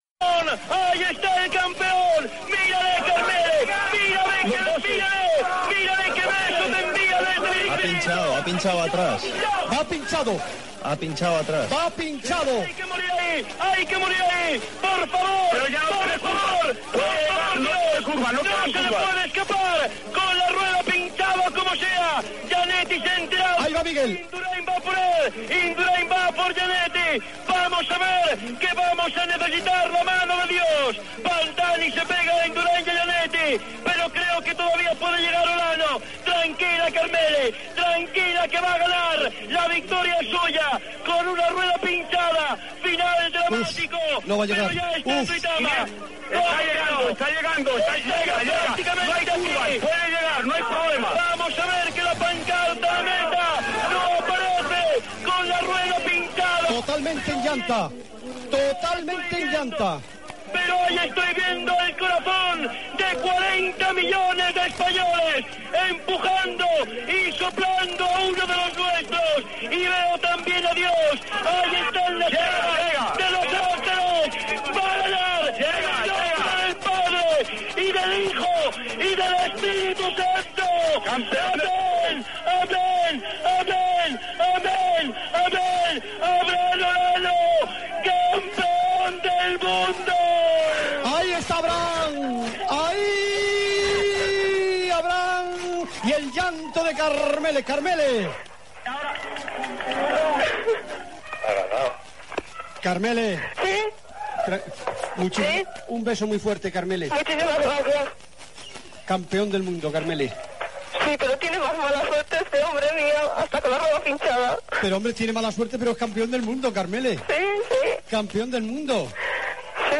Narració dels instant finals de la cursa en línia masculina del Campionat del Món de Ciclisme 1995 disputat a Duitama i Paipa, Colòmbia. Abraham Olano guanya la medalla d'oro i Miguel Induráin, la de plata.
Esportiu